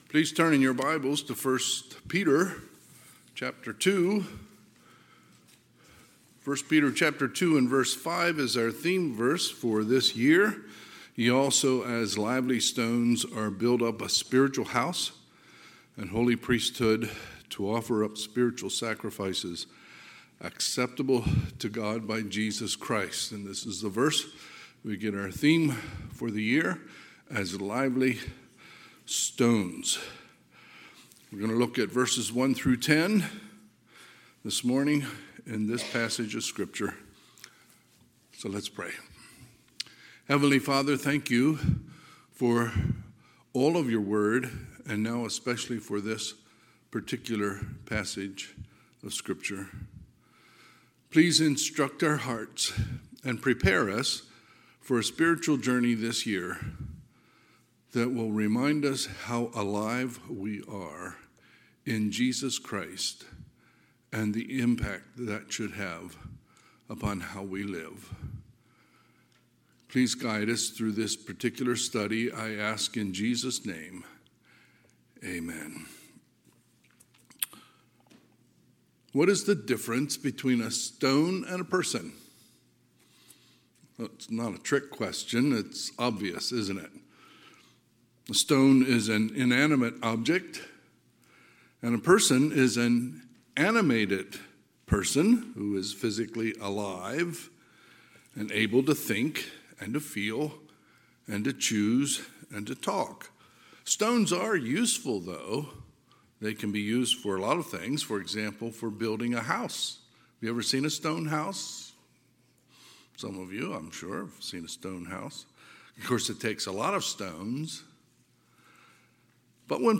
Sunday, January 7, 2023 – Sunday AM